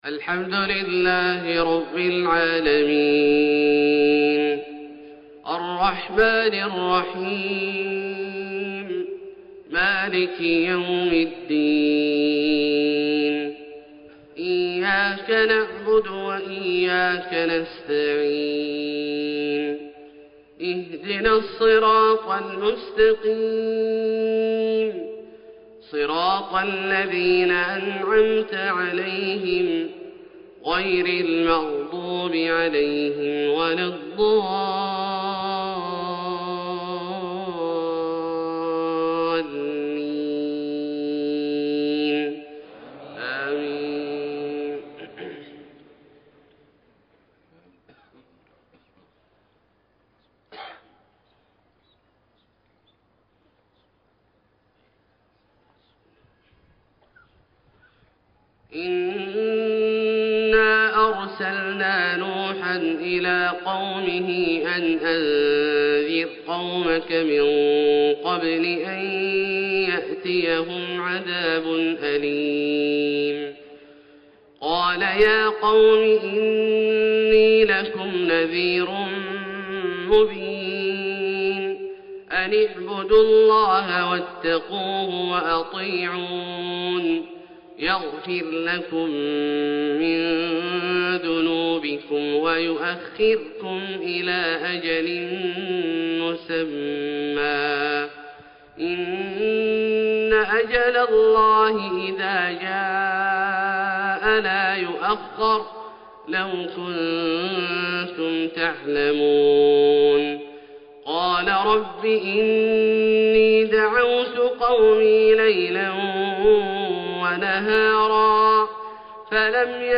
فجر 6-3-1433هـ سورة نوح > ١٤٣٣ هـ > الفروض - تلاوات عبدالله الجهني